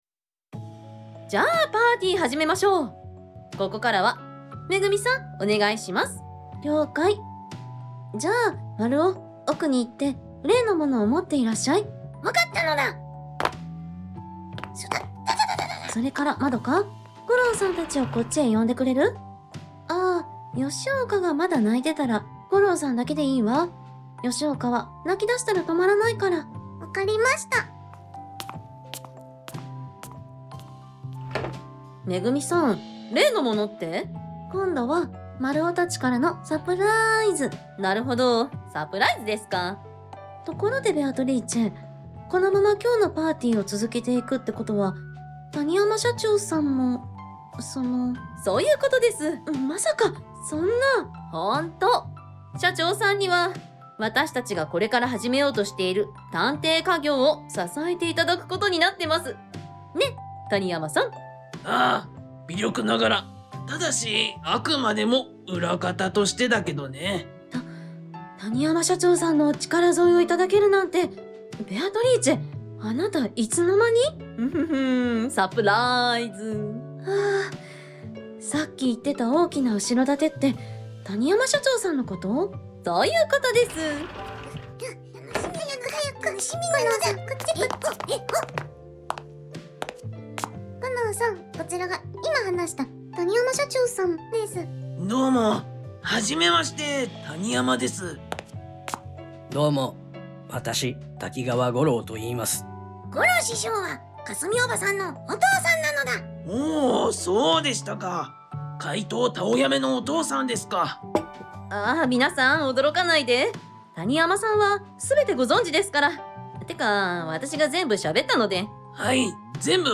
1 ラジオドラマ『怪盗たをやめ』シーズン2第十四話（最終話） 13:38